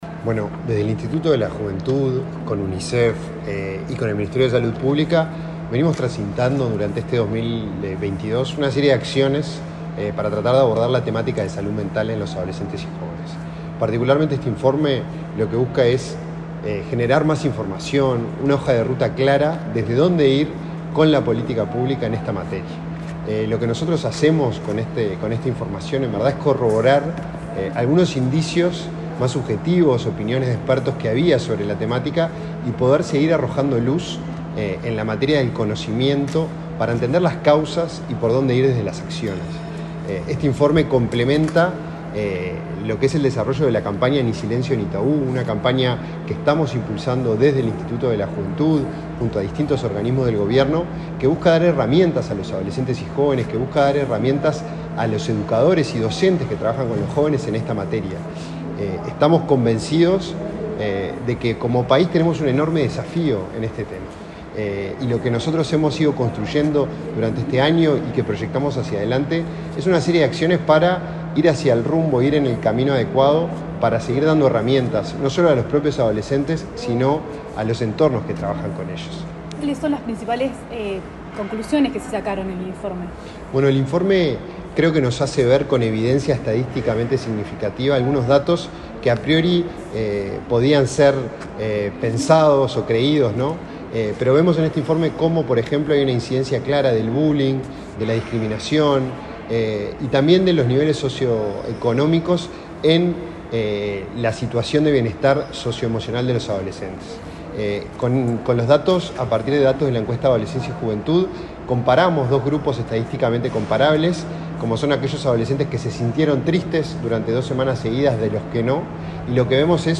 Entrevista al director del INJU
El director del Instituto Nacional de la Juventud (INJU), Felipe Paullier, dialogó con Comunicación Presidencial sobre la presentación del informe de